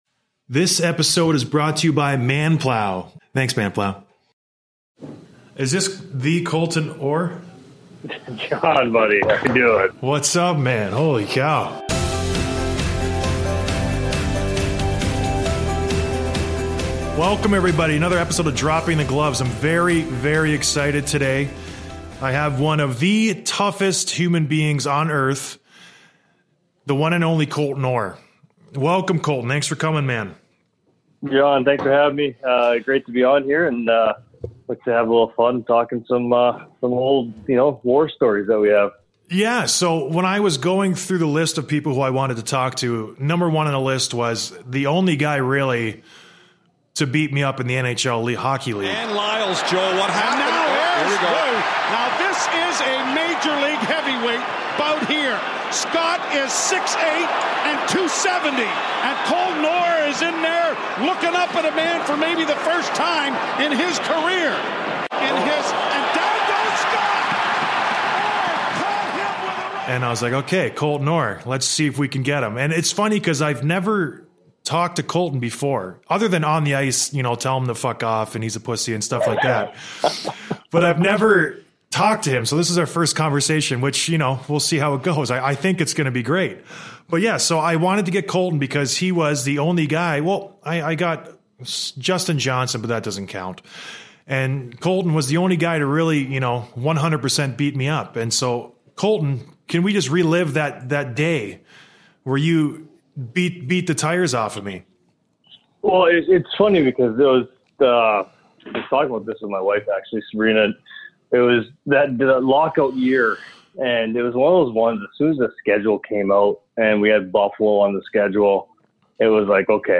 An Interview with Colton Orr, The Only Guy to Beat Me In a Fight and the Kessel Incident
Colton was kind enough to call in and have a chat about some hockey.